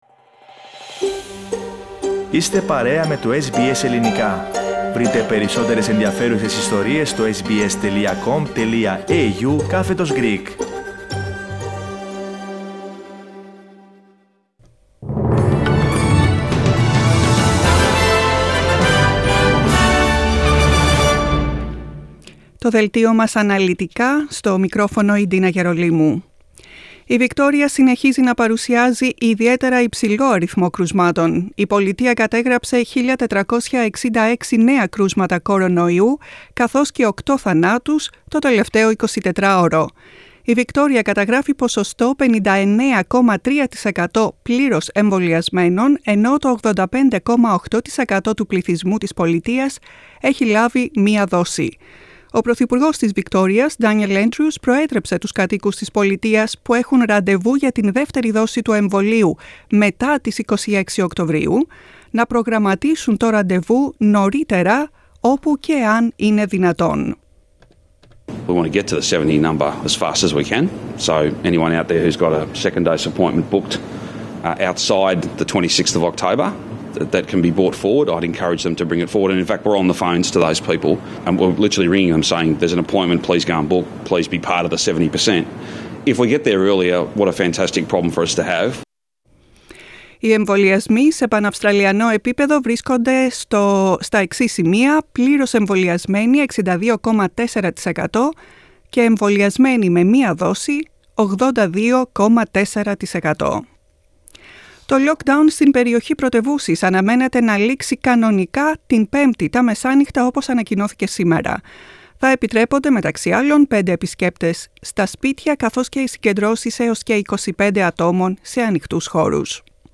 The main bulletin of the Greek Program on Tuesday 12.10.21